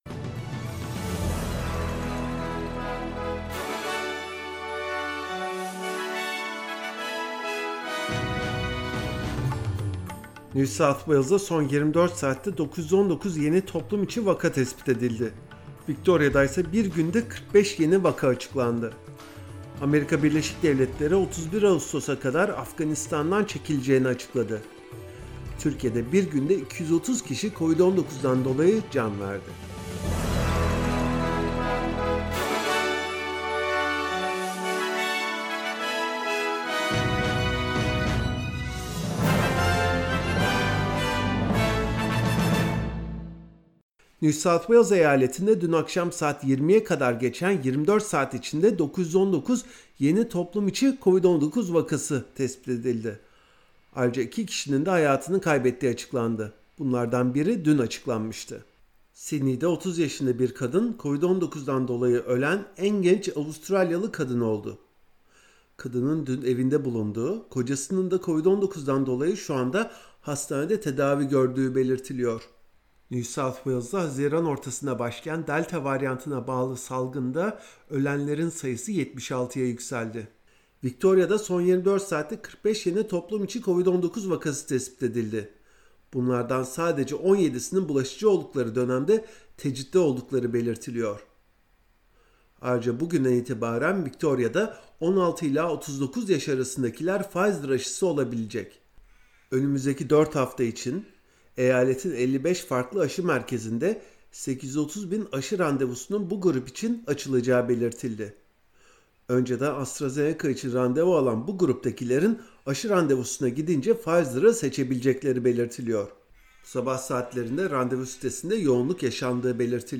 SBS Türkçe Haberler 25 Ağustos